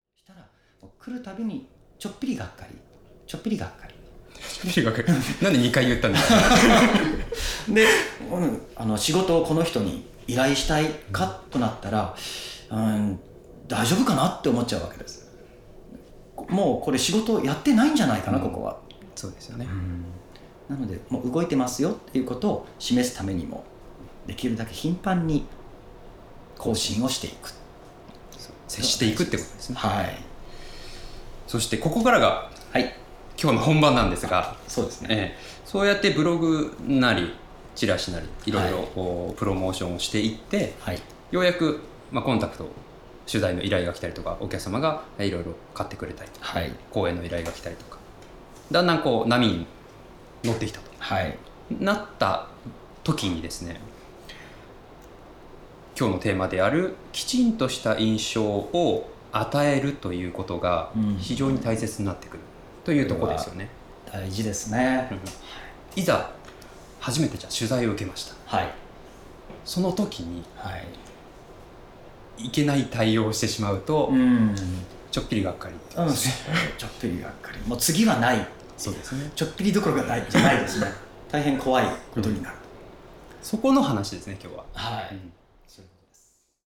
それでは音声講座でお会いしましょう。